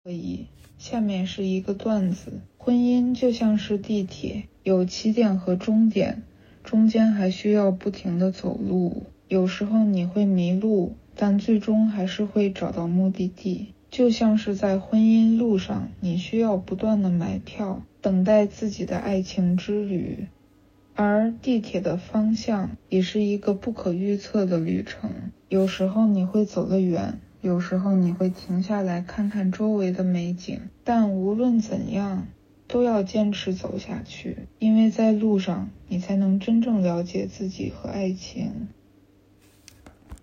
并随手丢了个类似的段子。
婚姻和坐地铁的段子.m4a